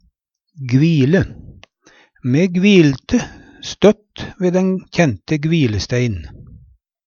gvile - Numedalsmål (en-US)
DIALEKTORD PÅ NORMERT NORSK gvile kvile Infinitiv Presens Preteritum Perfektum gvile gvile gvilte gvilt Eksempel på bruk Me gvilte støtt ve den kjente Gvilestein.